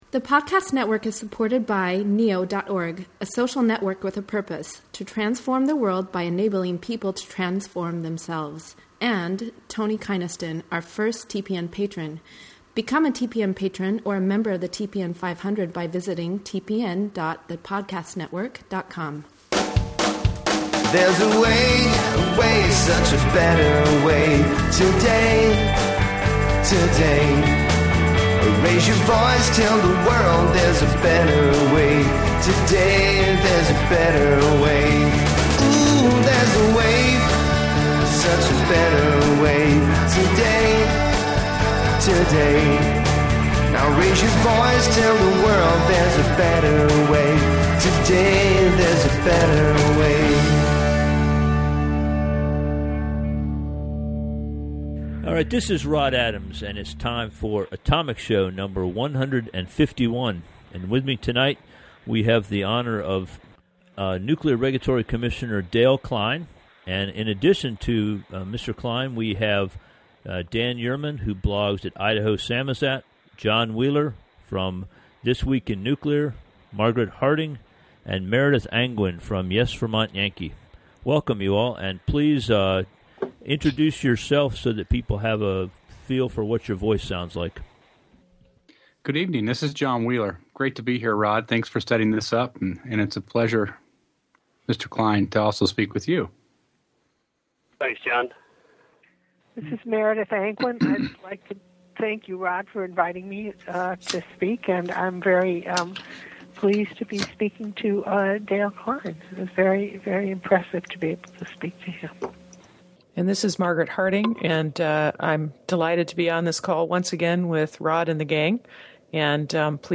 In this episode, you will hear questions from the following members of the pro-nuclear blogging community: